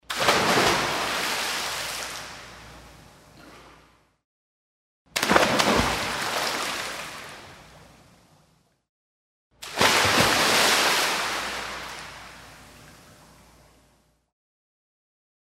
На этой странице собраны разнообразные звуки прыжков в воду: от легких всплесков до мощных ударов о поверхность.
Шум прыжка в бассейн